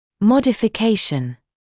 [ˌmɔdɪfɪ’keɪʃn][ˌмодифи’кейшн]модификация; изменение